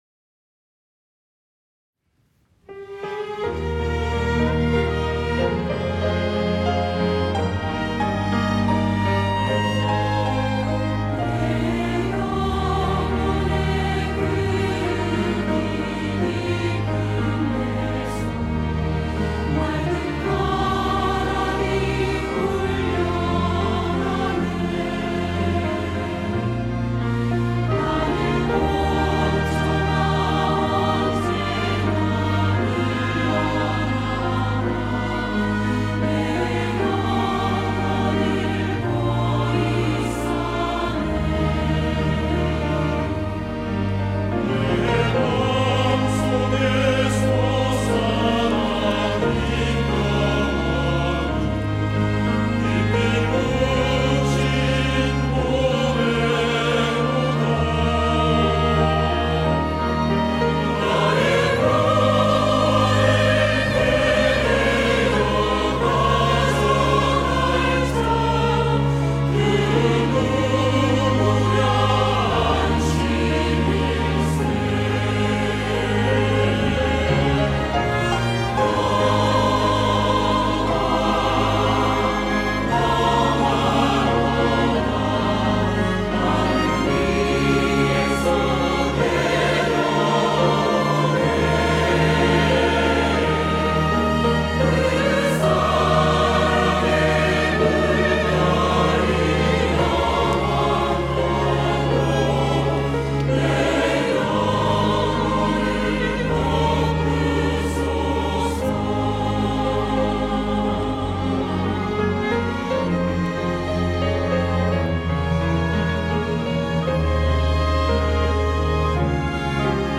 호산나(주일3부) - 내 영혼의 그윽히 깊은데서
찬양대